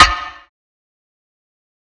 snare (vibes).wav